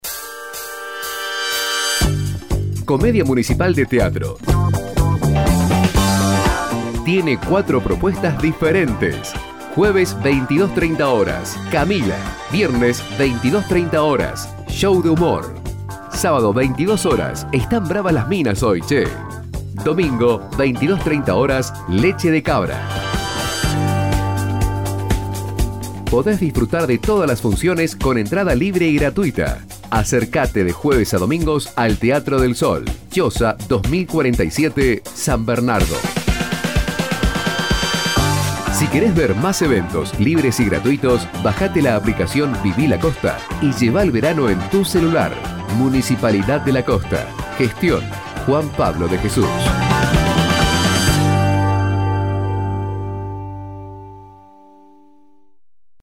Spot-Comedia-Municipal.mp3